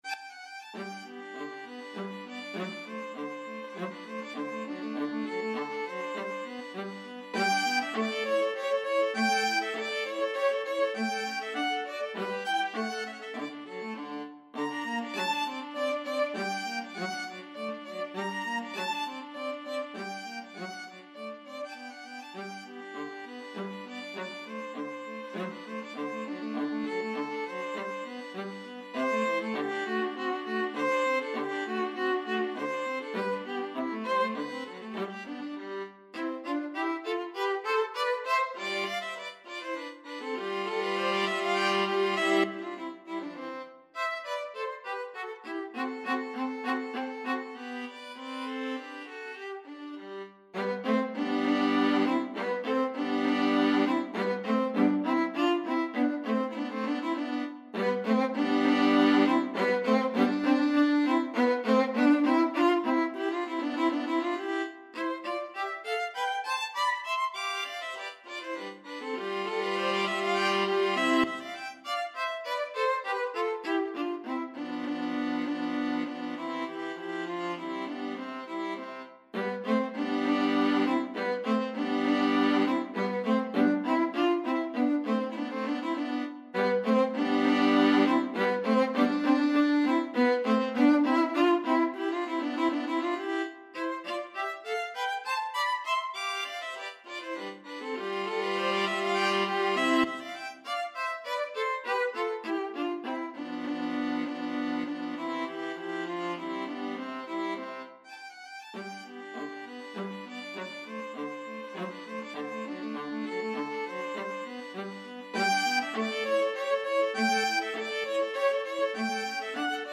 Moderato
3/4 (View more 3/4 Music)
Classical (View more Classical Violin-Viola Duet Music)